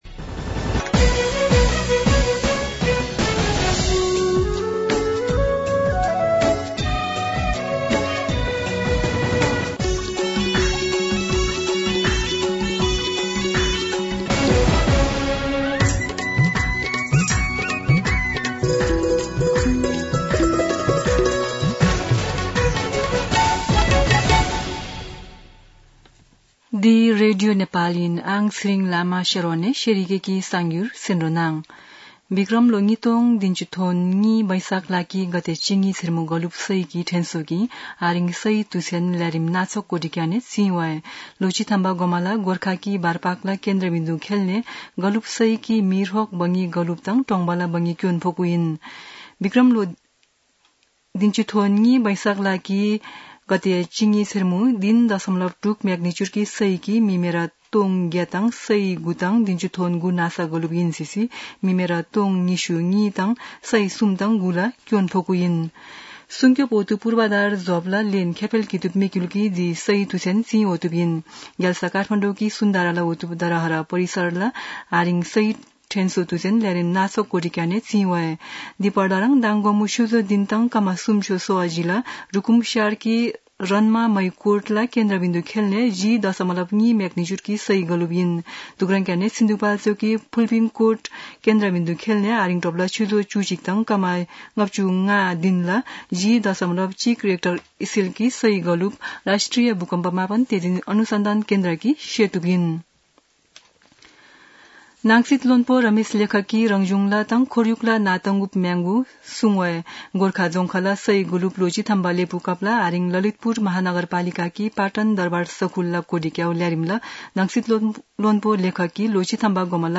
शेर्पा भाषाको समाचार : १२ वैशाख , २०८२
Sherpa-News-8.mp3